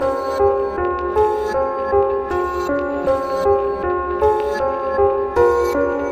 Tag: 157 bpm Trap Loops Piano Loops 1.03 MB wav Key : C